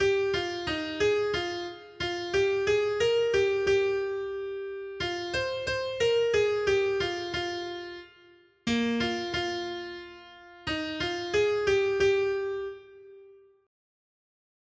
Noty Štítky, zpěvníky ol600.pdf responsoriální žalm Žaltář (Olejník) 600 Skrýt akordy R: Plesejte Bohu, který nám pomáhá. 1.